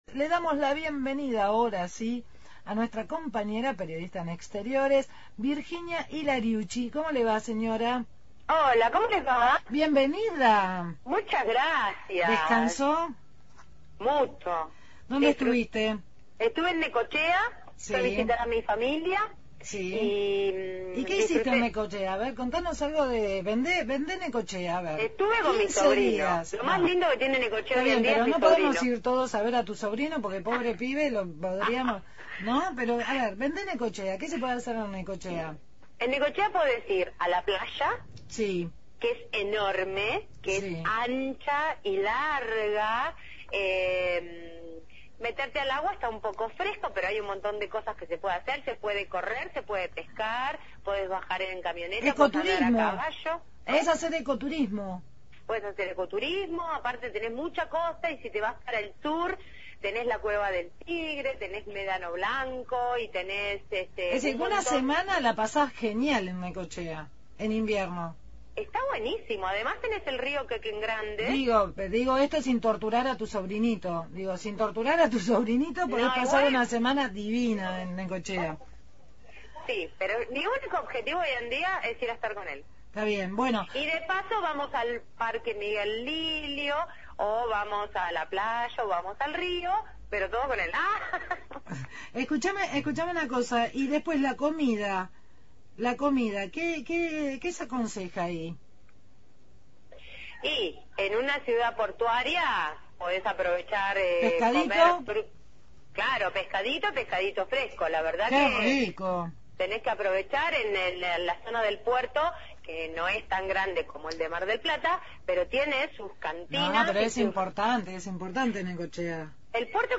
Móvil/ Celebración de la Pachamama en La Plata – Radio Universidad